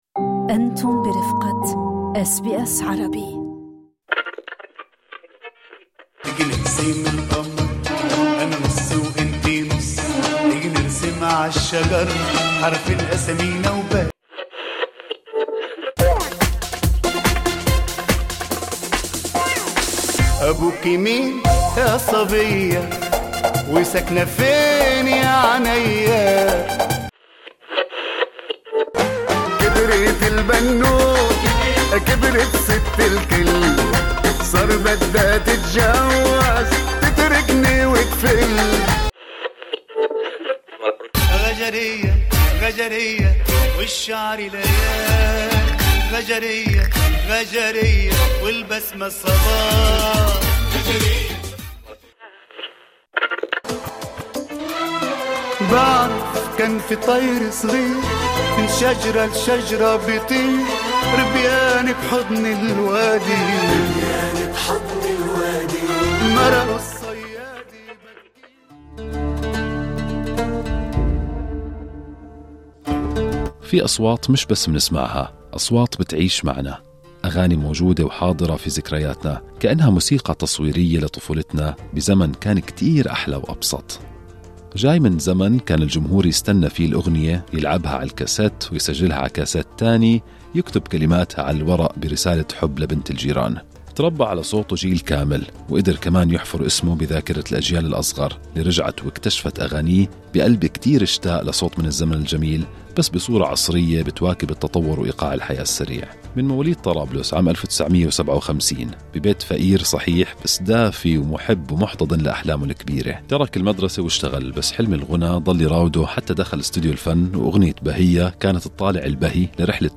في لقاء طبعته العفوية والكثير من الحنين إلى "زمن لن يتكرر"، فتح النجم العربي وليد توفيق صحفات من ذاكرته الفنية والإنسانية في هذا اللقاء عبر أثير Good Morning Australia، مستعيداً محطات رحلة امتدت من أحياء "أم الفقير" طرابلس في شمال لبنان، إلى الصفوف الأولى للنجومية العربية، ومتوقفاً عند فلسفته في الفن ومعنى الاستمرارية وعودته المنتظرة للقاء جمهور اشتاق له في أستراليا.
Lebanese singer and actor, Walid Toufic Credit: Walid Toufic's official Facebook page
بصوت هادئ وذاكرة تلونت بمحطات مضيئة مع أساطير الغناء في الذاكرة الجمعية العربية، استعاد وليد توفيق ملامح الطفولة الأولى في طرابلس، المدينة التي شكّلت وجدانه وبقيت حاضرة في تفاصيله مهما ابتعد.